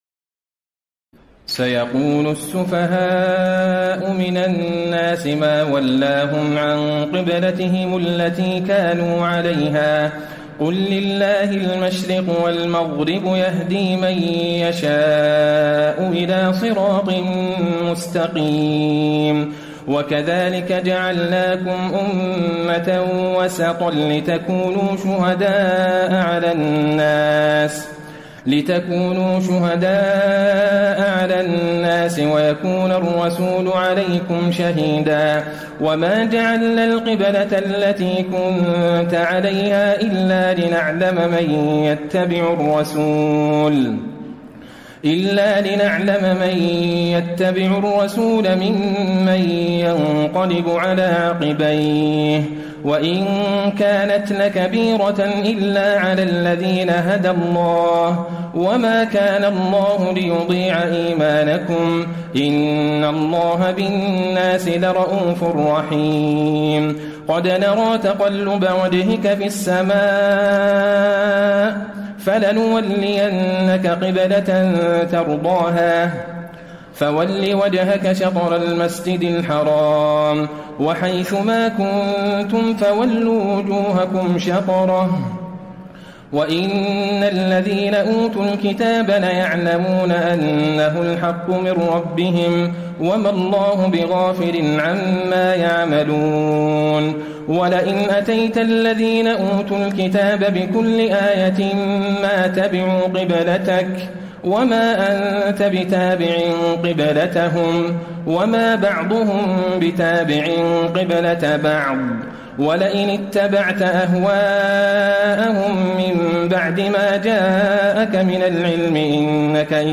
تهجد ليلة 22 رمضان 1434هـ من سورة البقرة (142- 218) Tahajjud 22 st night Ramadan 1434H from Surah Al-Baqara > تراويح الحرم النبوي عام 1434 🕌 > التراويح - تلاوات الحرمين